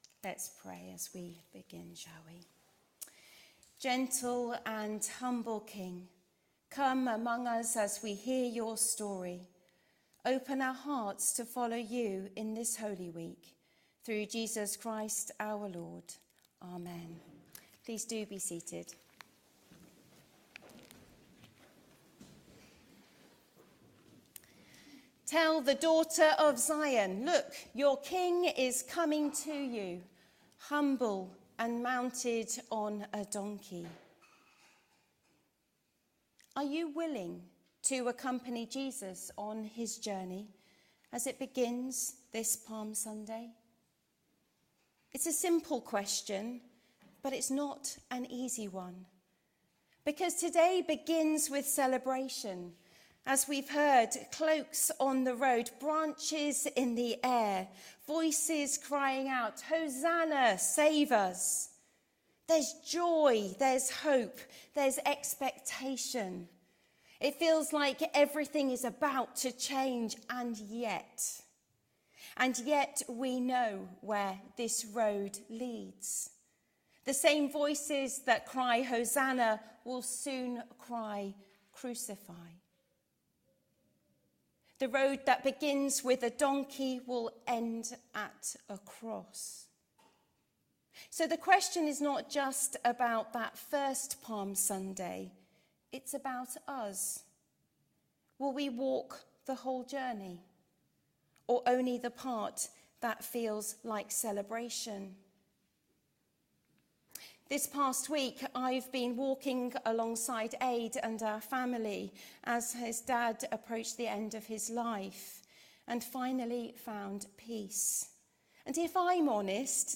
HomeSermonsDo not just watch but walk…